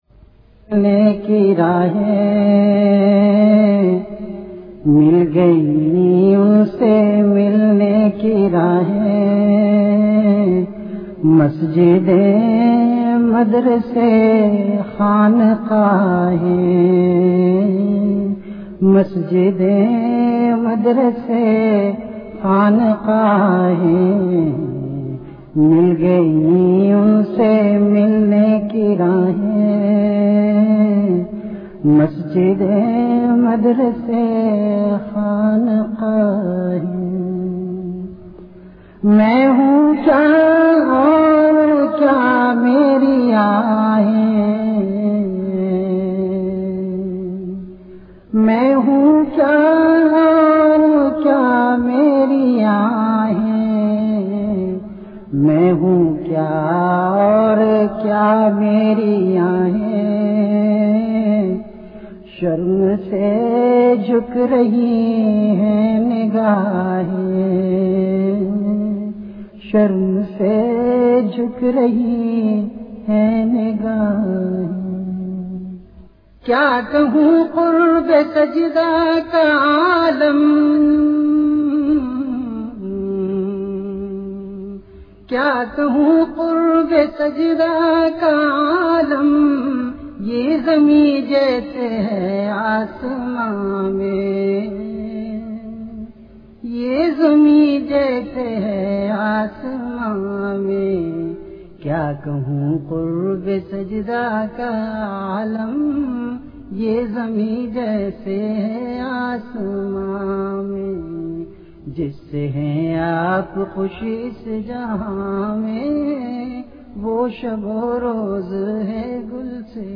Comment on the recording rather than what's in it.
VenueKhanqah Imdadia Ashrafia Event / TimeAfter Isha Prayer